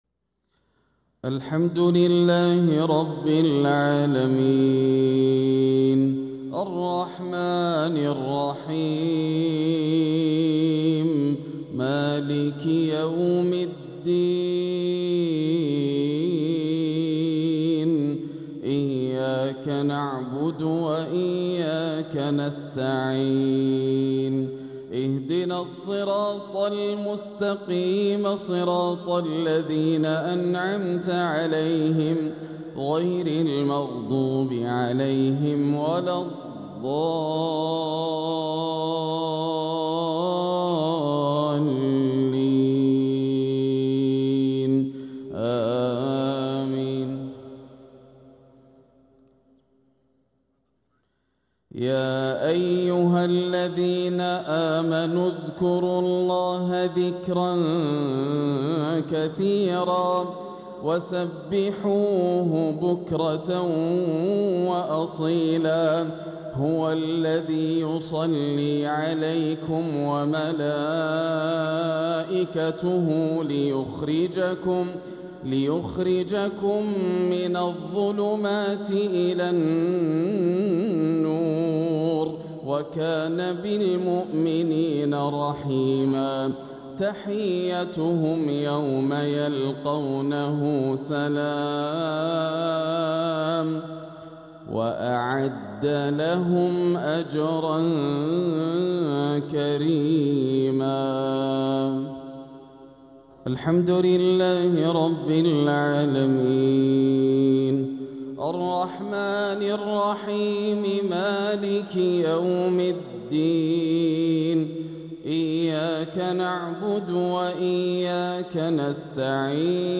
تلاوة من سورة الأحزاب | مغرب الجمعة 22 ربيع الآخر 1446هـ > عام 1446 > الفروض - تلاوات ياسر الدوسري